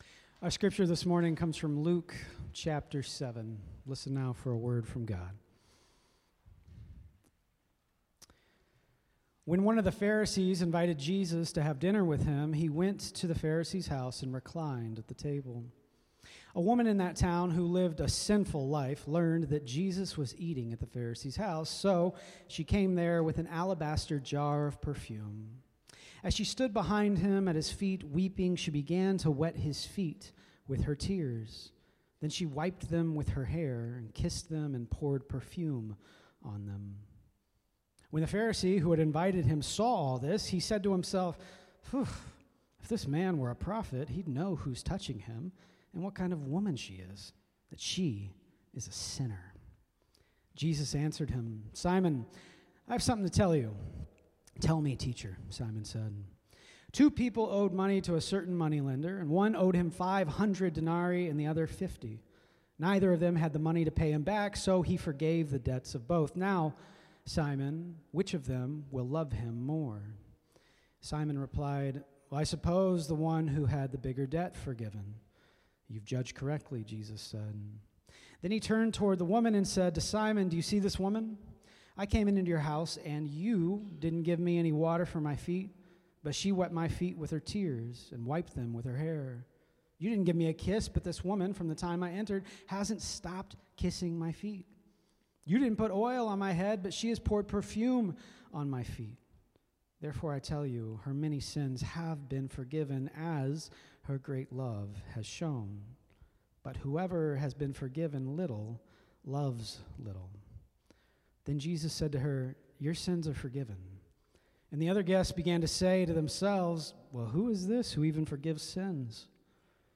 Sermons | Fort Street Presbyterian Church